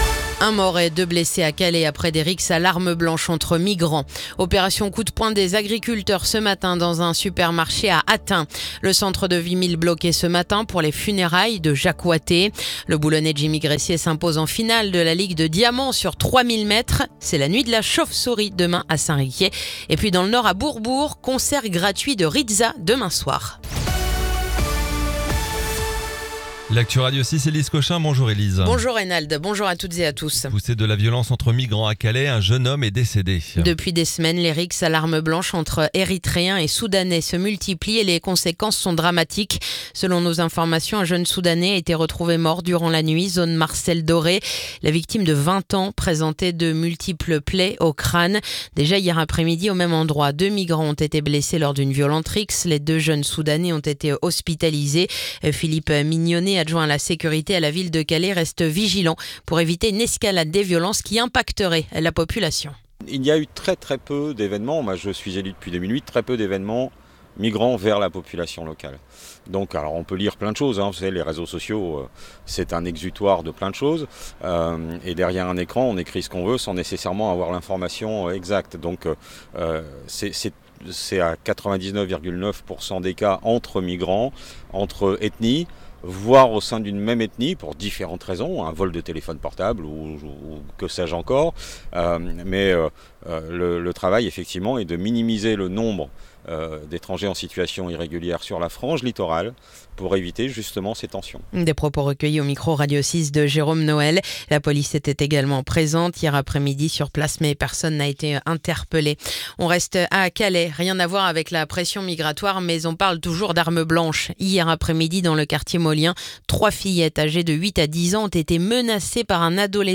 Le journal du vendredi 29 août